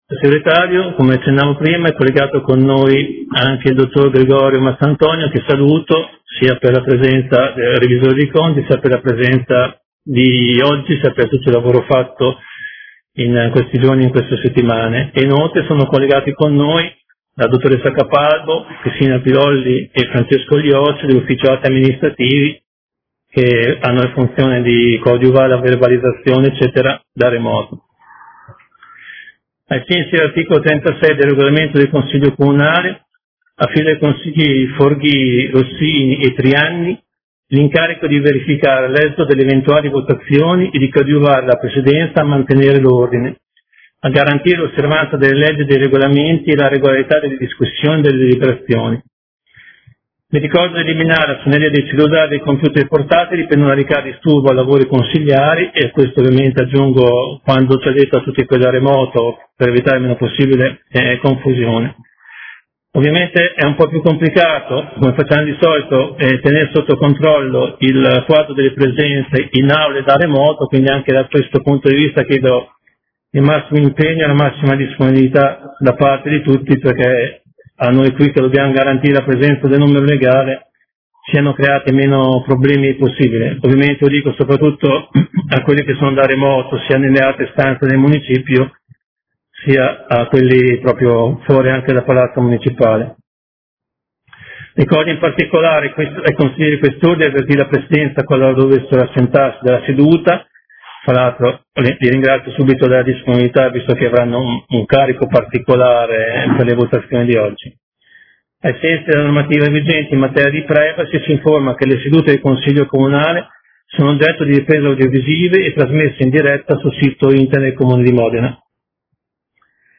Comunicazioni del Presidente sulle modalità di svolgimento del Consiglio Comunale, minuto di silenzio per vittime covid-19, applauso per personale sanitario, protezione civile e addetti nella gestione dell'emergenza.